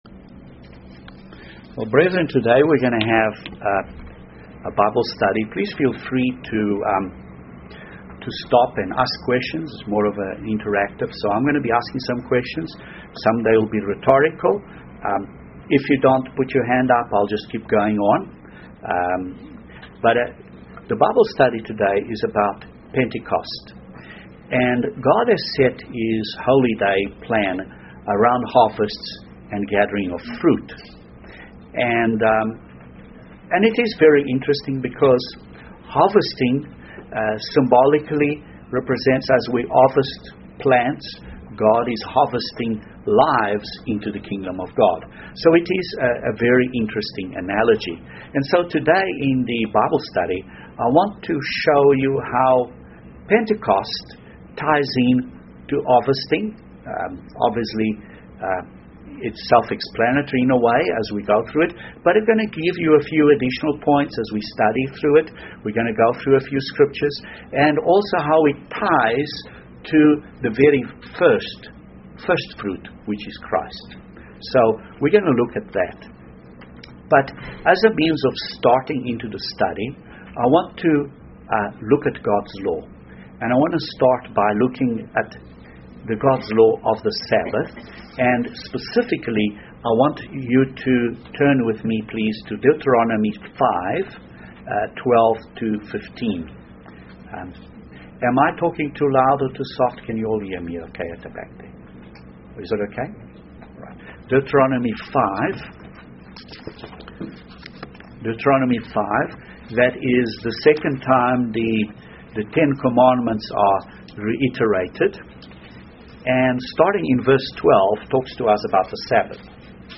Specifically Pentecost represents the harvest of the firstfruits of the Holy Spirit and is directly linked to Jesus Christ as The First of the firstfruits. This sermon expounds the scriptures which demonstrate this connection.